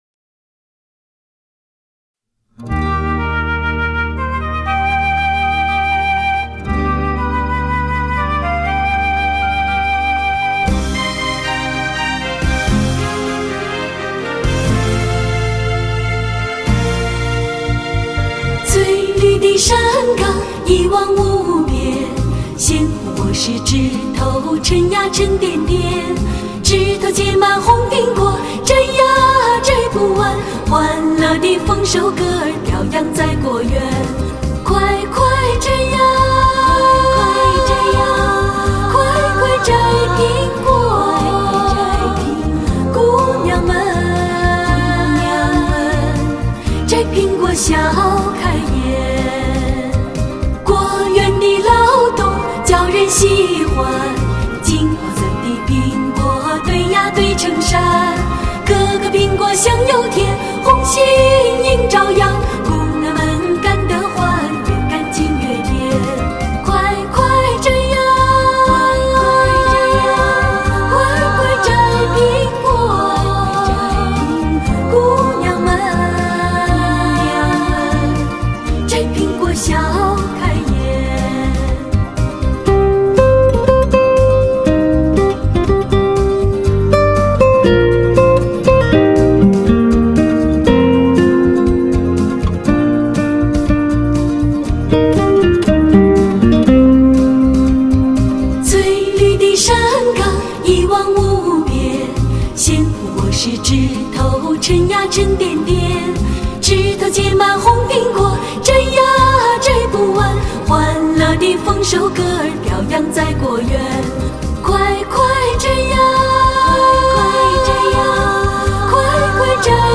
音乐风格：Other/PoP